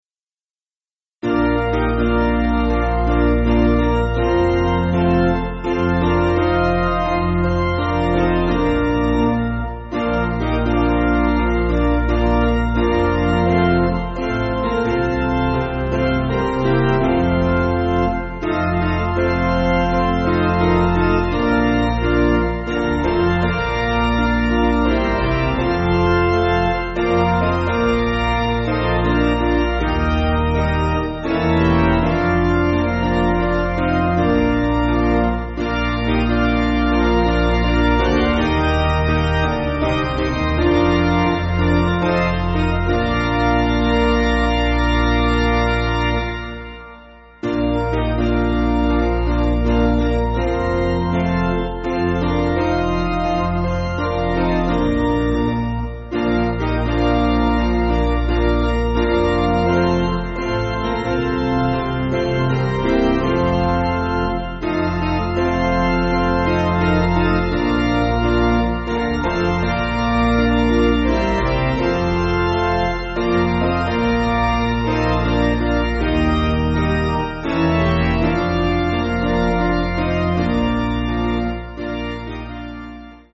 Basic Piano & Organ
(CM)   4/Bb